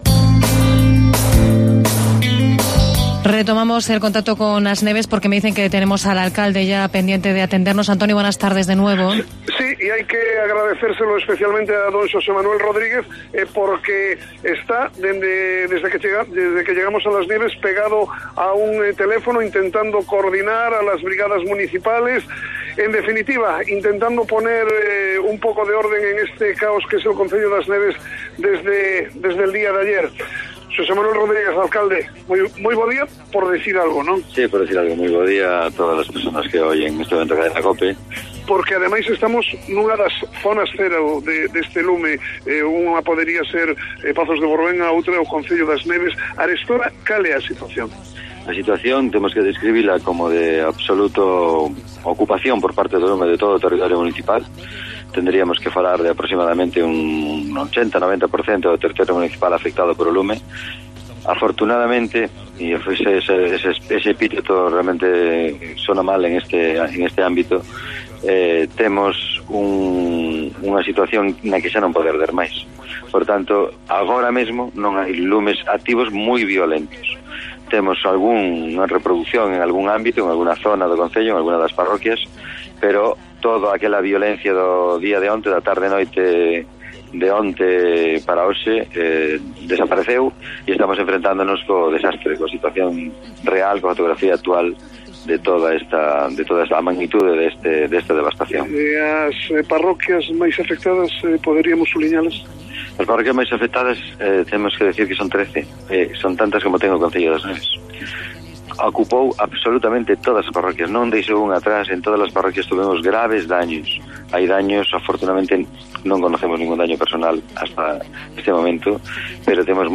Por los micrófonos de Cope+ Vigo hoy pasó el alcalde de As Neves, Xose Manuel Rodríguez, uno de los municipios más afectados por los incendios que en las últimas 24 horas han asolado el sur de la provincia.